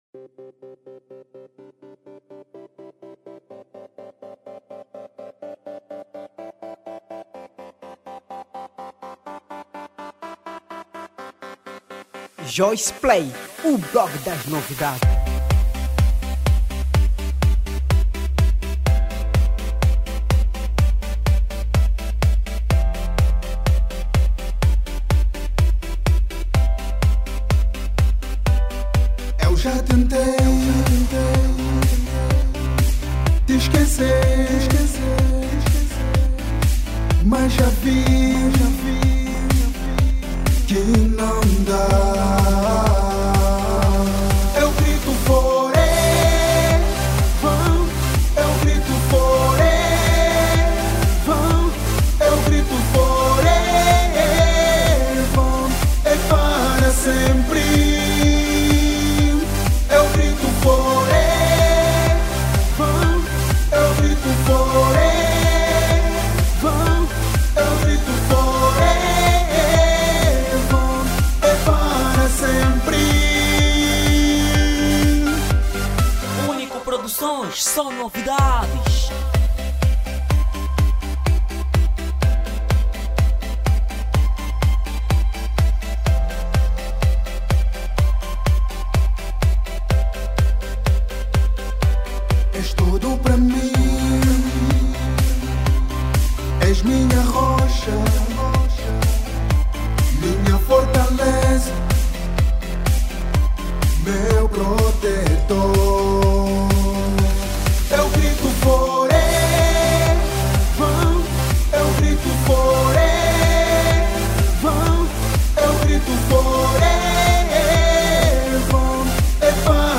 Género: Pop